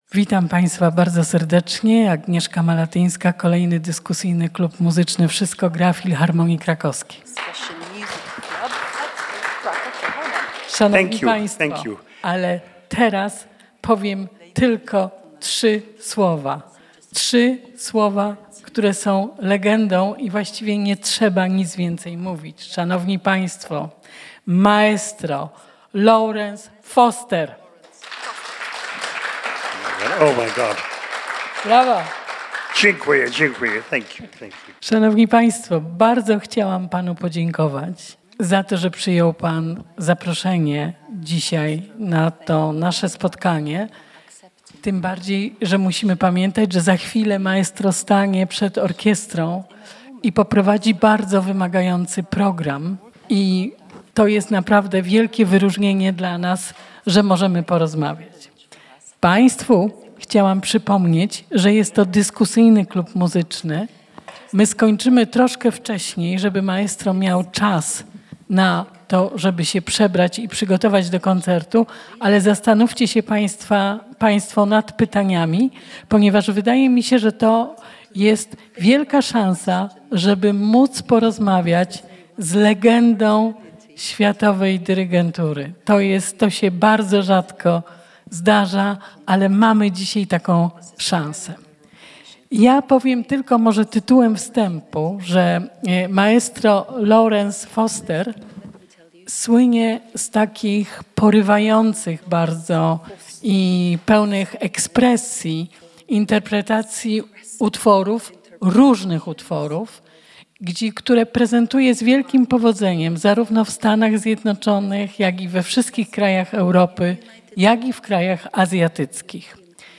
W tym sezonie spotkania klubowe, będące tematycznie powiązane z koncertami Filharmonii Krakowskiej, są rejestrowane przez Radio Kraków i w tydzień później w formie podcastu będą udostępniane na naszej stronie internetowej.